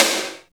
45.05 SNR.wav